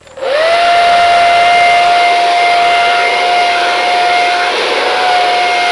Big Hairdryer Sound Effect
Download a high-quality big hairdryer sound effect.
big-hairdryer.mp3